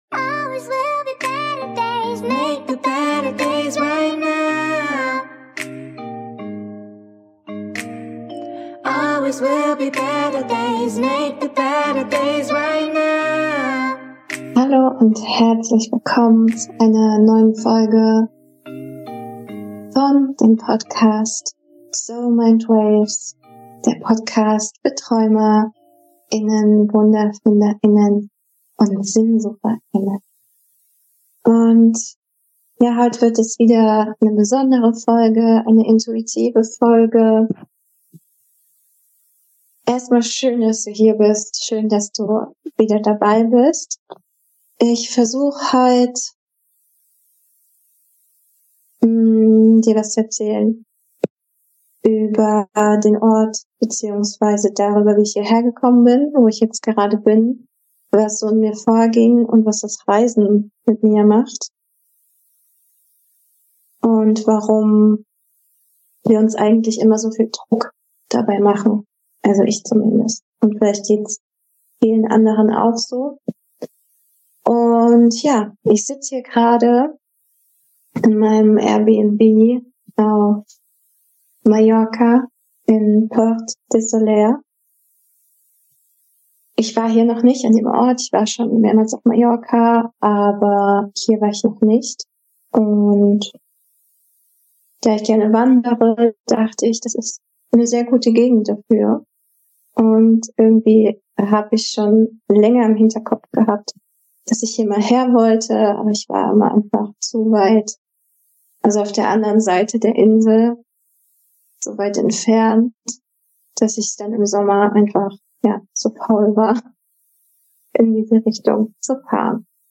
Was ich auf Reisen mache, um mein Nervensystem zu regulieren. Hey, in dieser Folge ist die Soundqualität leider nicht wie gewohnt - das liegt an meinem aktuellen Ortswechsel.